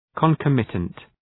Προφορά
{kɒn’kɒmıtənt} (Επίθετο) ● συνακόλουθος (Ουσιαστικό) ● οπαδός